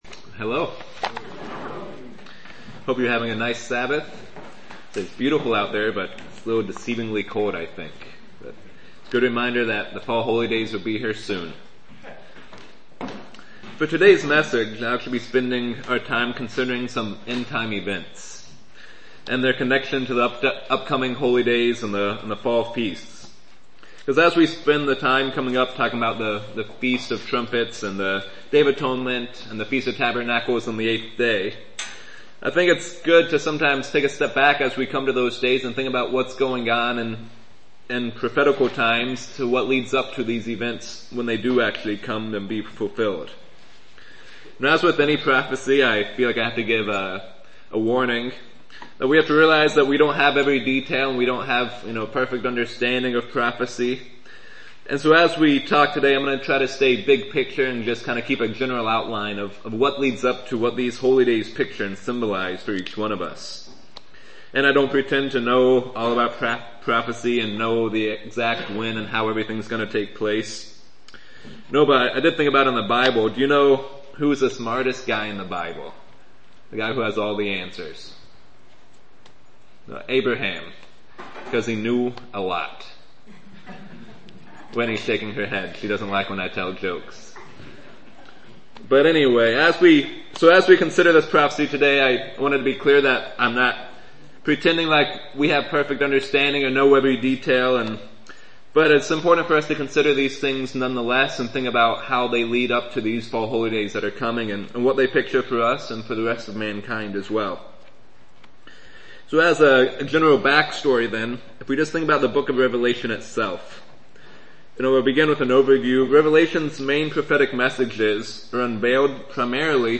How do the Seals of Revelations connect to end time events and the Holy Days? This sermon covers the general outline of the seals and how they apply to us and the fall Holy Days.